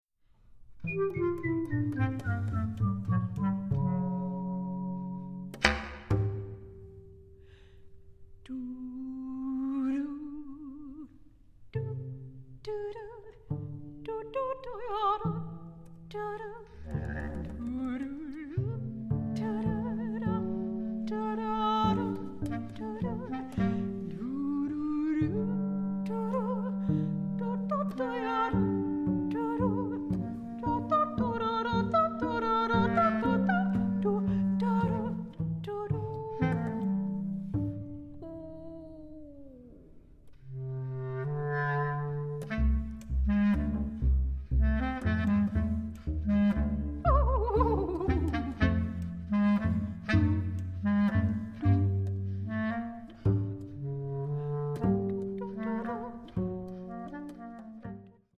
• Genres: Classical, Vocal